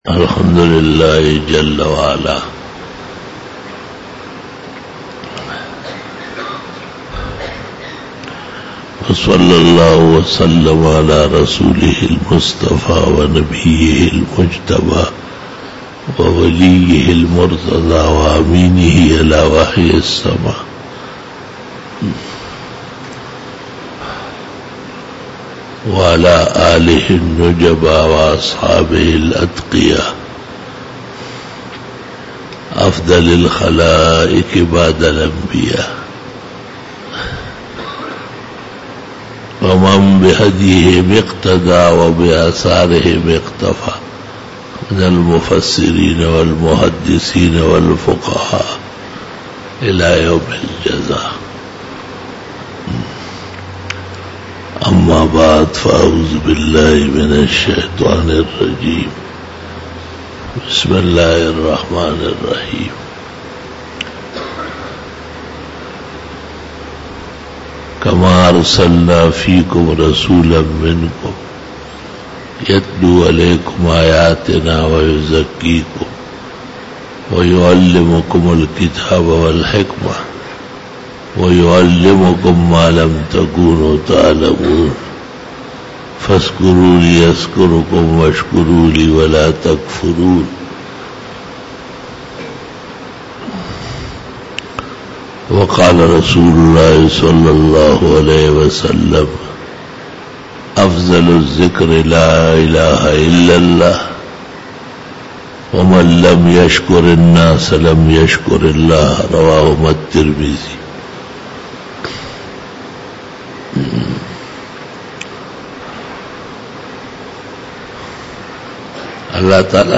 48 BAYAN E JUMA TUL MUBARAK (30 November 2018) (21 Rabi ul Awwal 1440H)